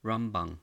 pronounced: RAHM-bahng
Pronunciation: RAHM-bahng